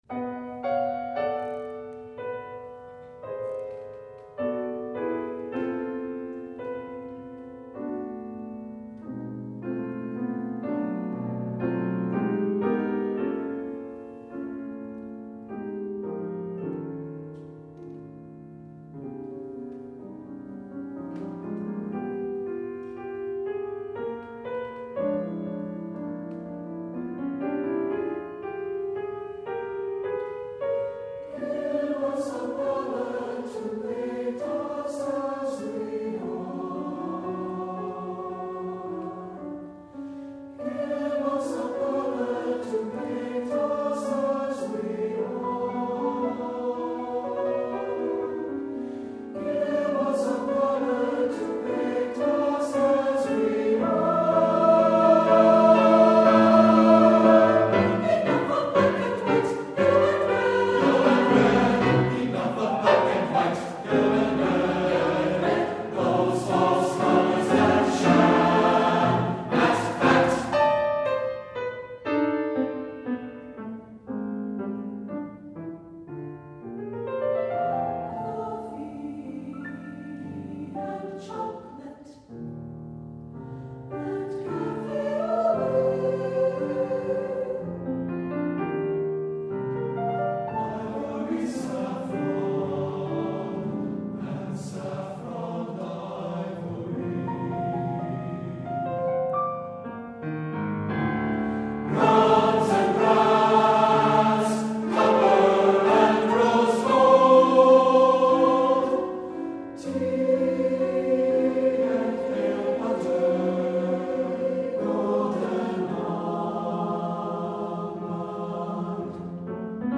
SATB, piano